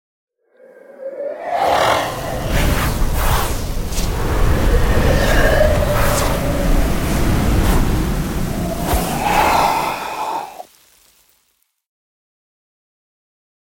جلوه های صوتی
دانلود صدای طوفان 23 از ساعد نیوز با لینک مستقیم و کیفیت بالا
برچسب: دانلود آهنگ های افکت صوتی طبیعت و محیط دانلود آلبوم صدای طوفان از افکت صوتی طبیعت و محیط